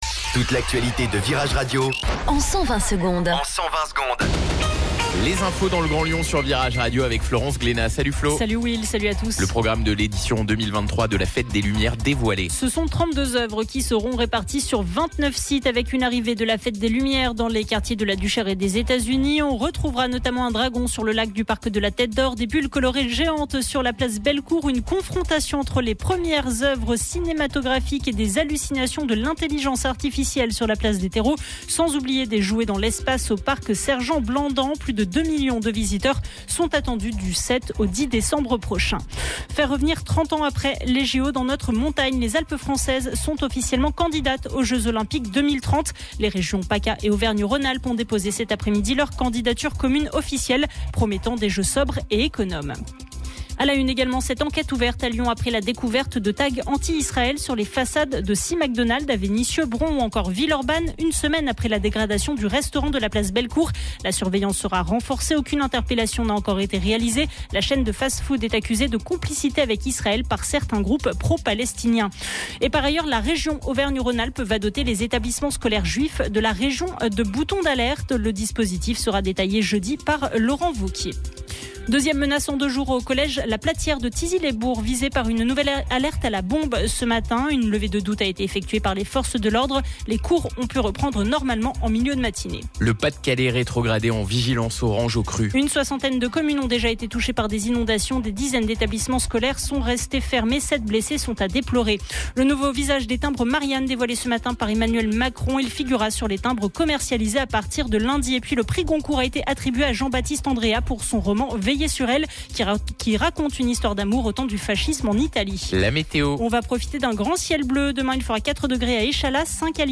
Flash Info Lyon